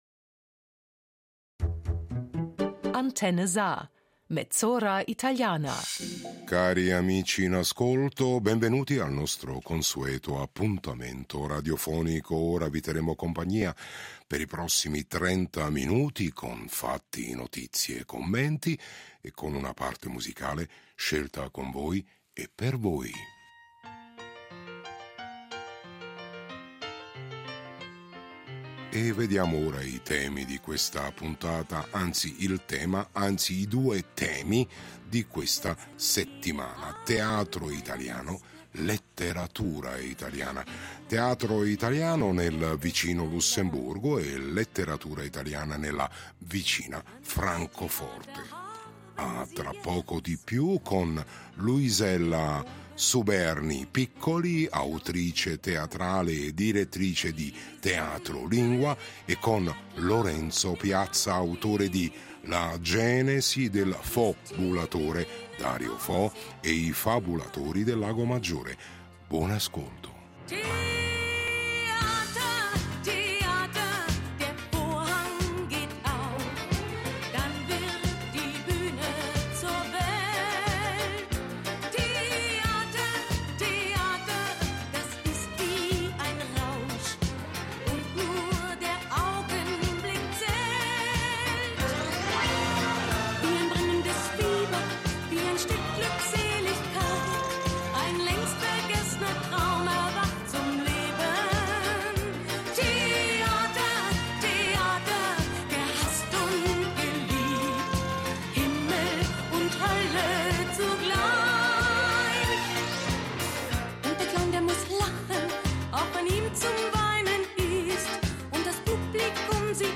Tema: Teatro italiano in Lussemburgo, letteratura italiana a Francoforte. Intervista